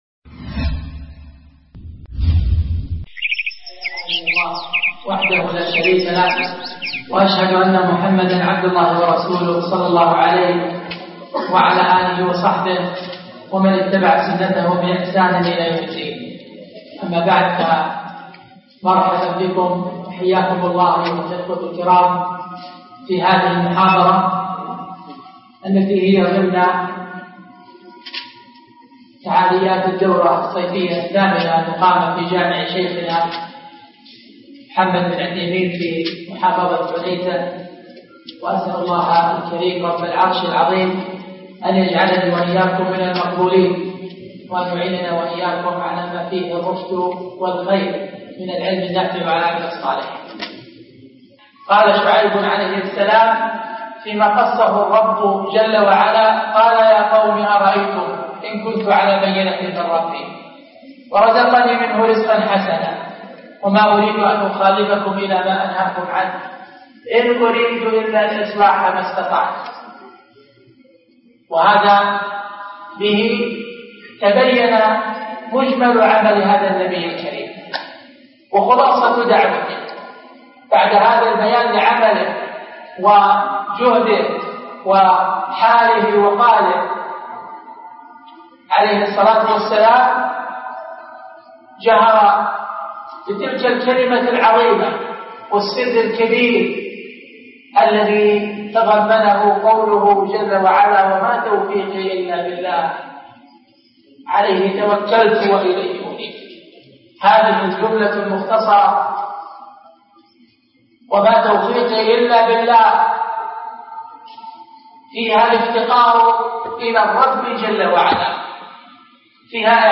التوفيق ضرورة حياتيه - محاضرة